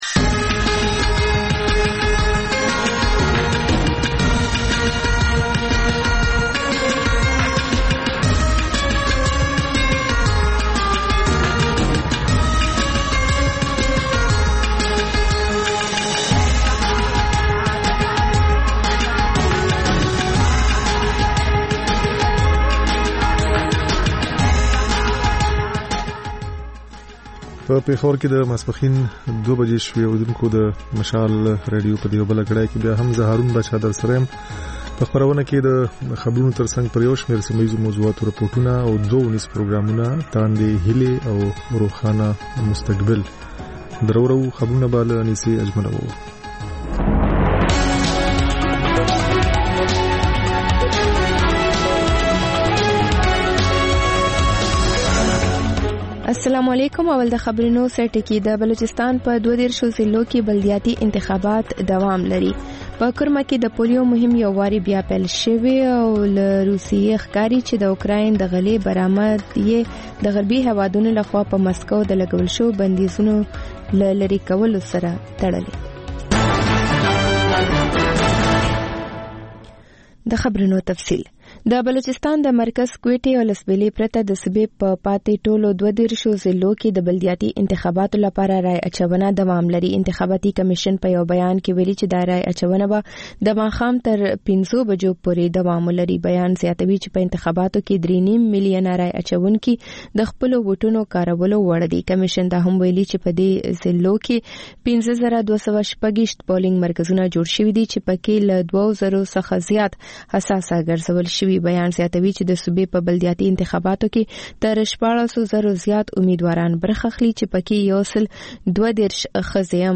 په دې خپرونه کې لومړی خبرونه او بیا ځانګړې خپرونه خپرېږي.